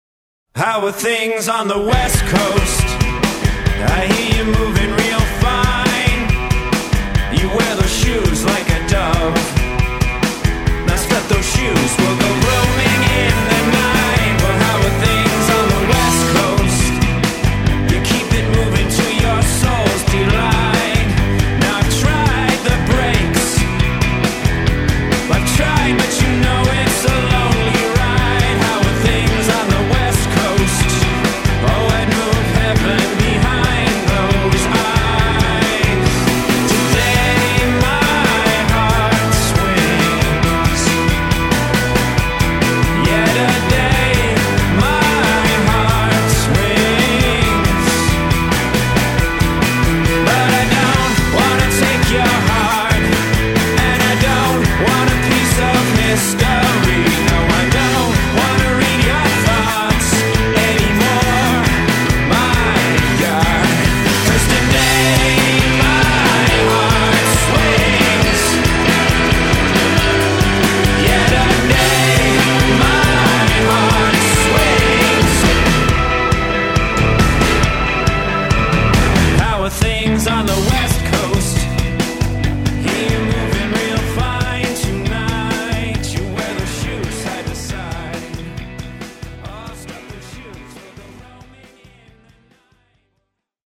dal basso profondo alle chitarre liriche
voce baritonale
new wave britannica degli anni ’80